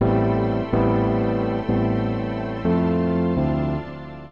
PIANO015_VOCAL_125_A_SC3(R).wav